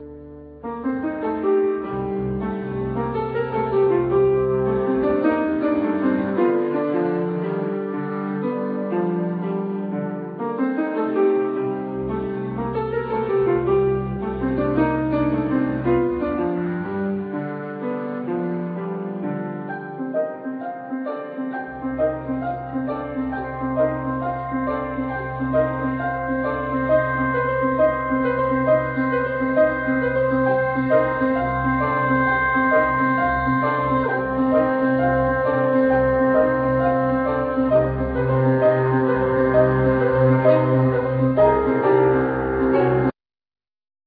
Violin
Bassoon
Piano
Double-Bass
Drums,Percussions
Clarinet
Strings Quartet
Organ